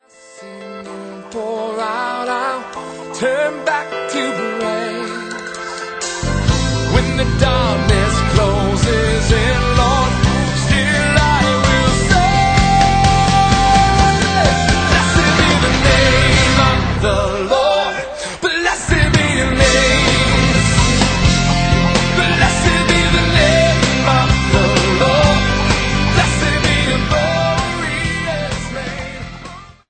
This passionate live recording
traditional & modern worship songs & hymns of faith